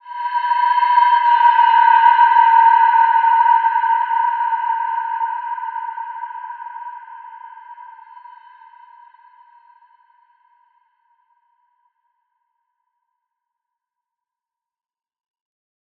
Wide-Dimension-B4-f.wav